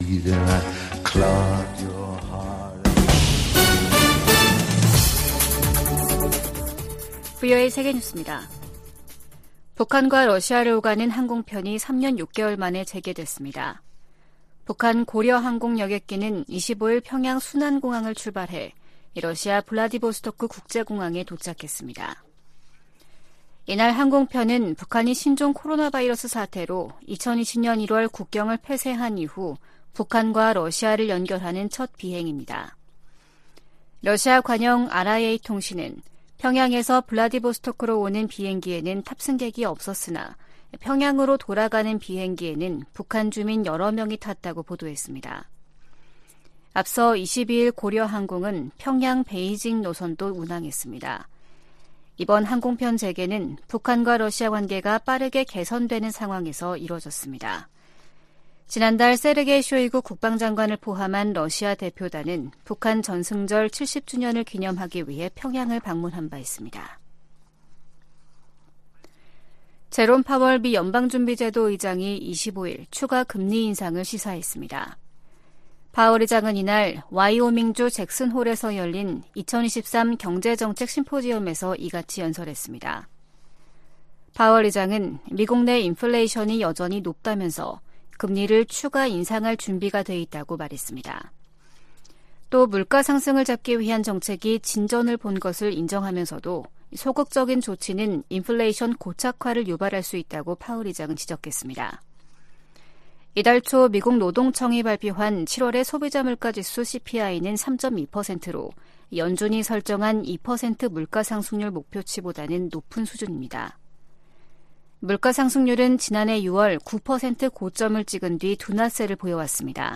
VOA 한국어 아침 뉴스 프로그램 '워싱턴 뉴스 광장' 2023년 8월 26일 방송입니다. 유엔 안전보장이사회가 25일 미국 등의 요청으로 북한의 위성 발사에 대한 대응 방안을 논의하는 공개 회의를 개최합니다. 미 국방부가 북한의 2차 정찰위성 발사를 비판하며 지역의 불안정을 초래한다고 지적했습니다. 북한 해킹조직이 탈취한 거액의 암호화폐를 자금세탁해 현금화할 가능성이 있다고 미국 연방수사국(FBI)이 경고했습니다.